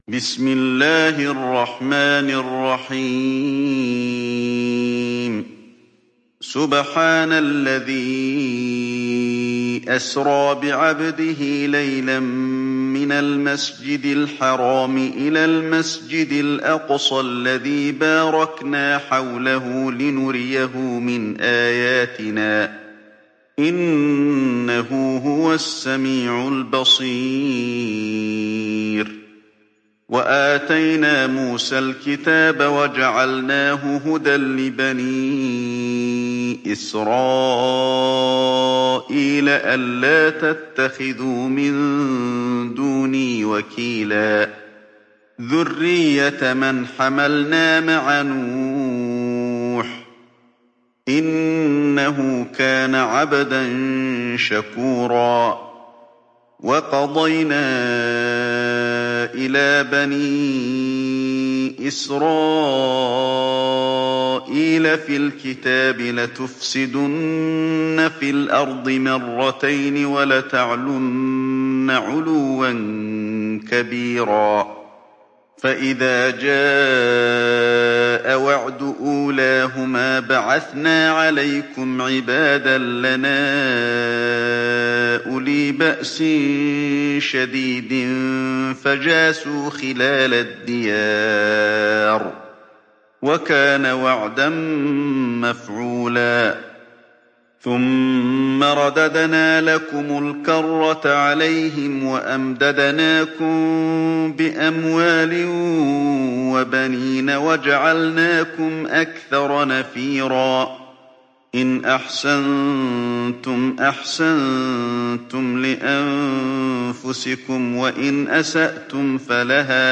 تحميل سورة الإسراء mp3 علي الحذيفي (رواية حفص)